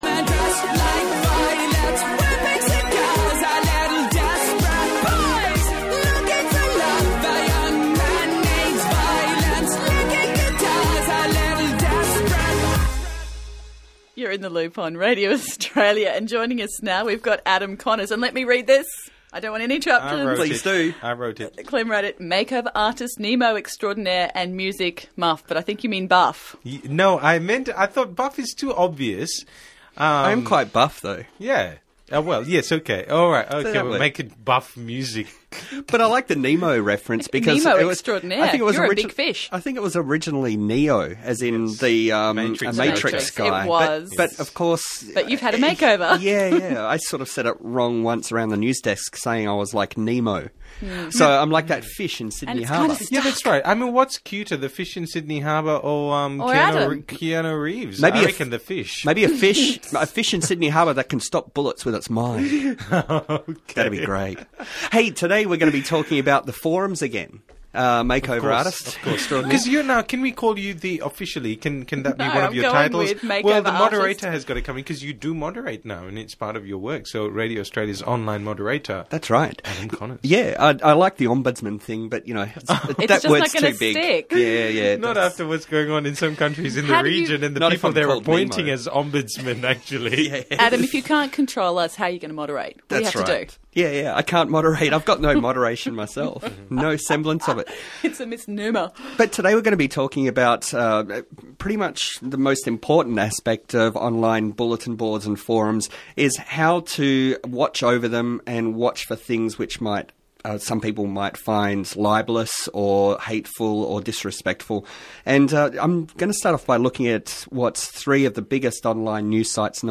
In the Loop interview about social media in the 2000s